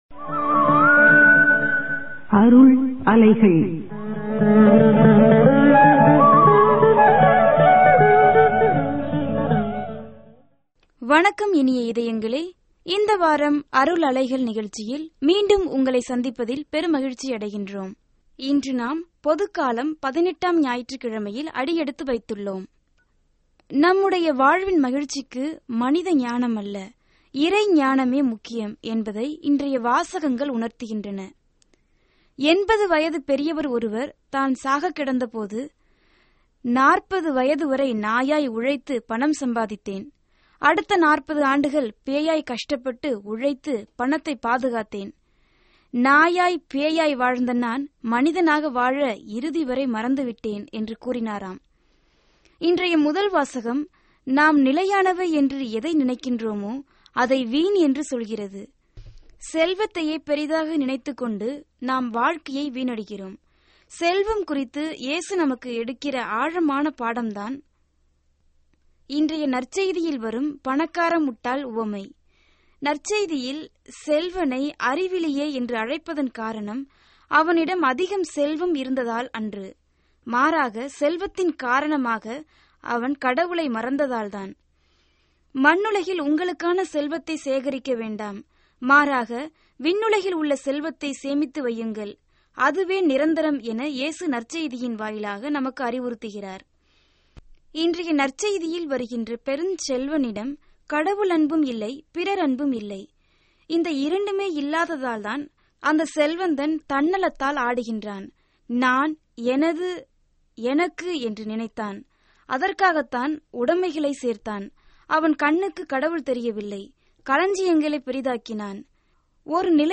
Directory Listing of mp3files/Tamil/Homilies/Ordinary Time/ (Tamil Archive)